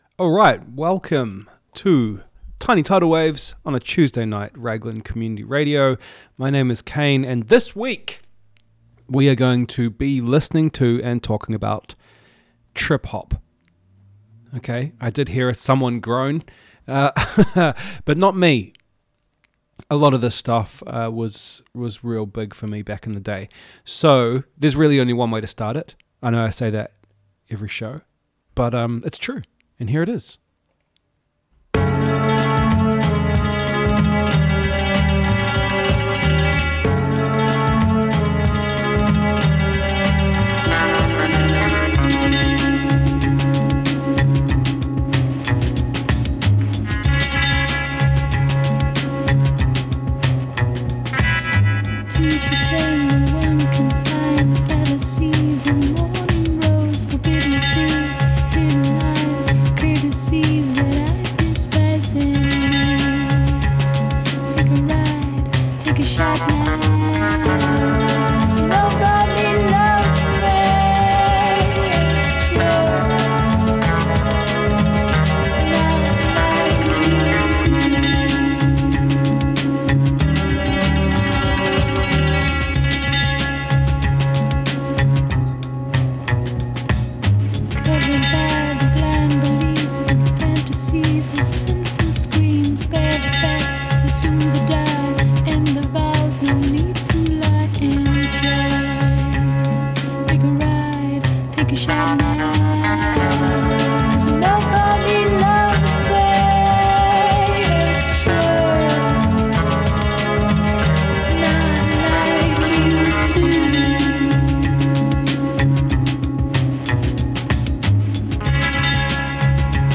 This week we're listening to the Trip Hop greats, who are all from the UK...except one.